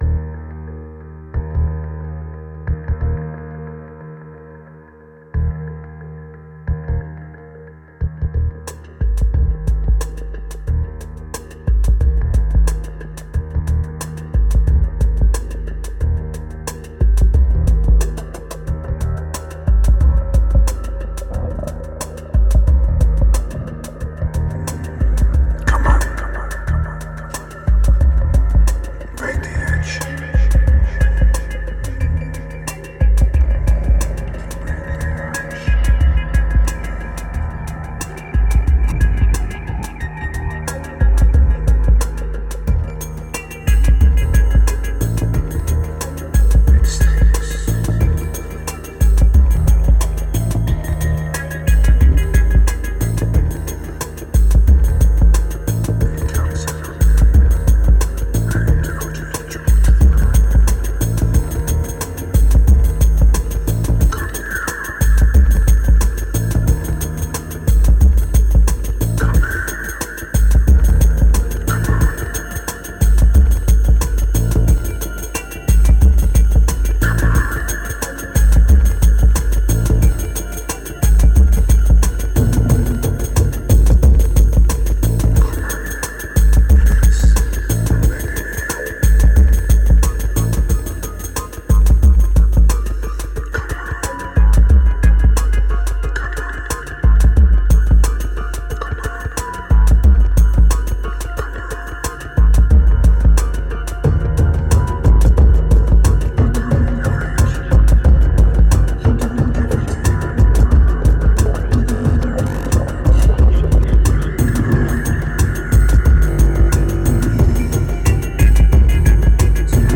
2156📈 - 38%🤔 - 90BPM🔊 - 2011-11-27📅 - -13🌟